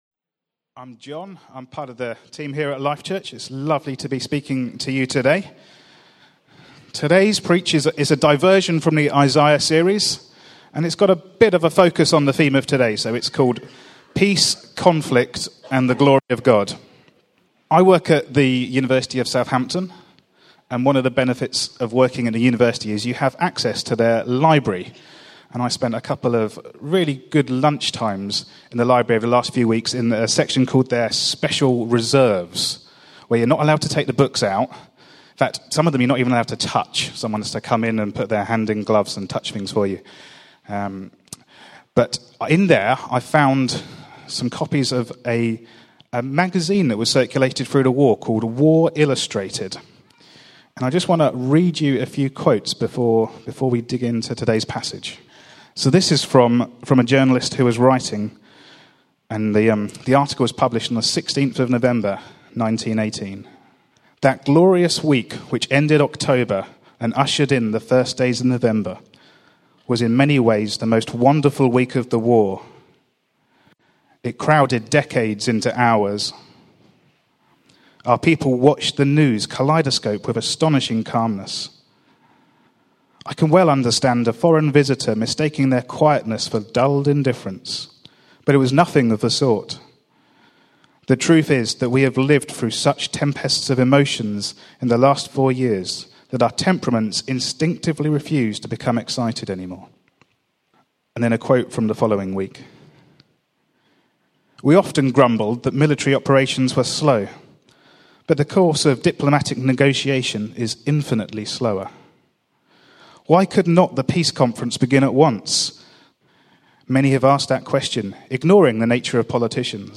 Service Type: Sunday Celebration